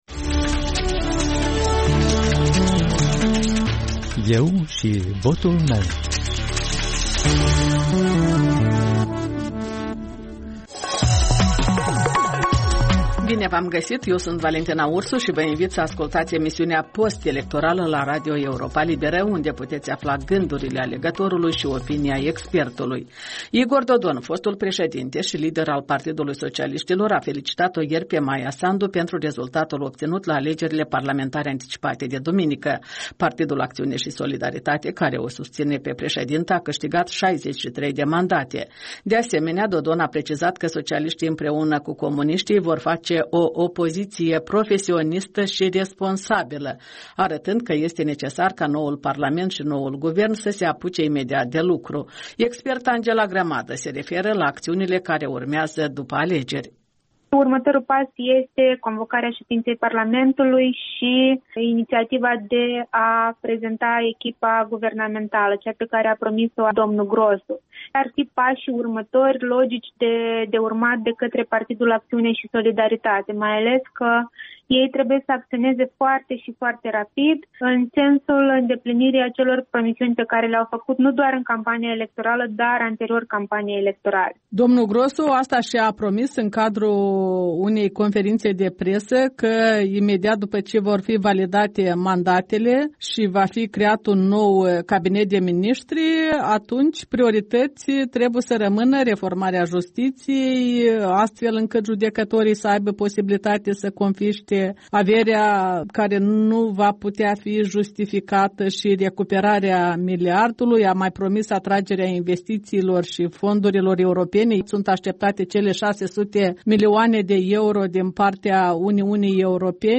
Radio Europa Liberă acoperă noul ciclu electoral cu interviuri, comentarii, analize și reportaje video din campania electorală.